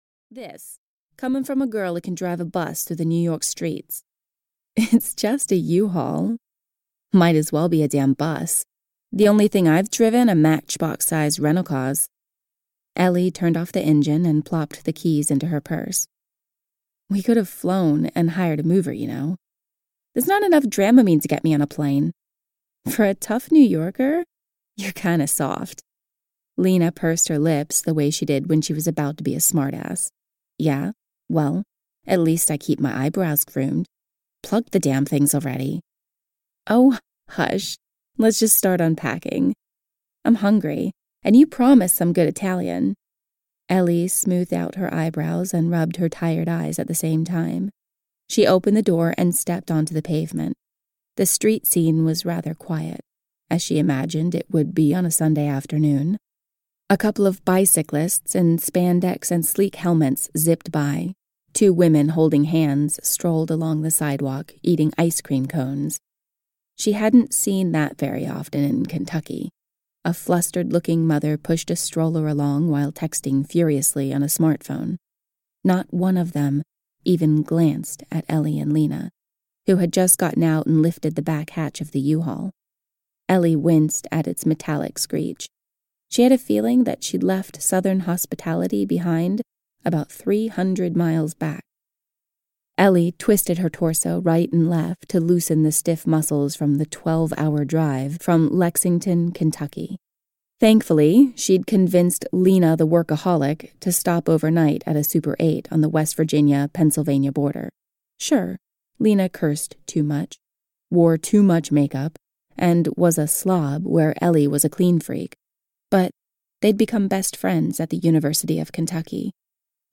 Drive Me Crazy (EN) audiokniha
Ukázka z knihy